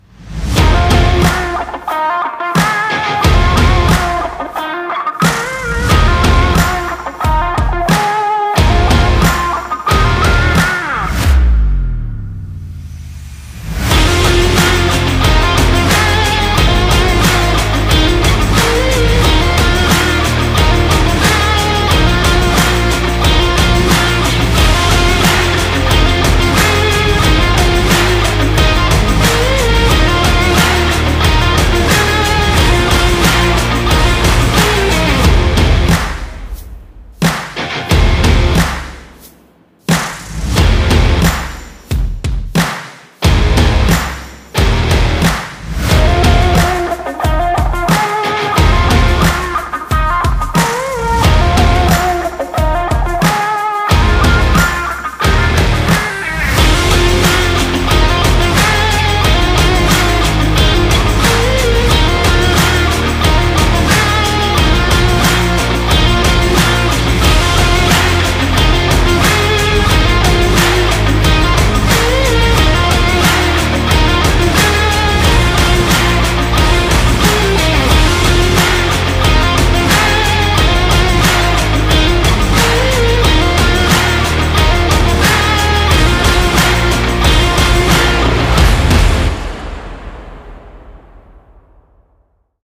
激情动感节奏热血沸腾活动BGM